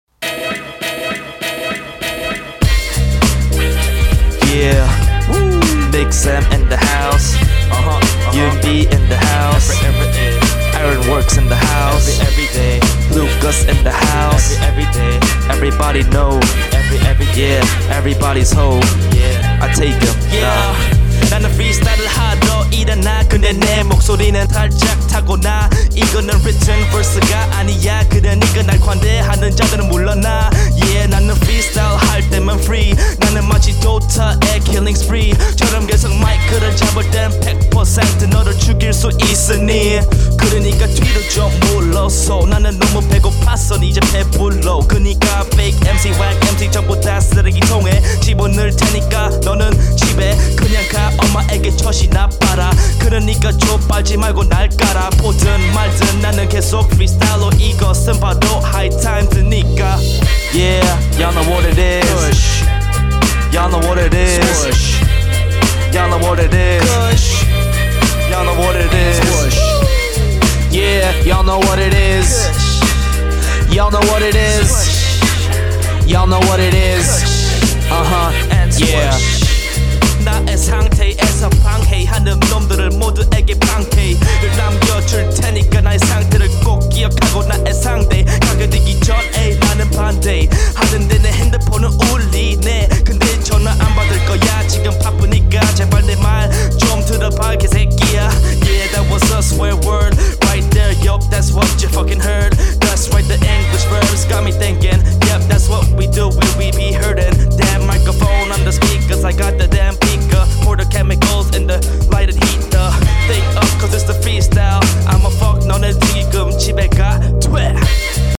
Freestyle 트랙 | 리드머 - 대한민국 힙합/알앤비 미디어
역시 freestyle 이다보니, 안맞는부분도 있어요. 물론, hook, 그리고 edit 은좀했고요.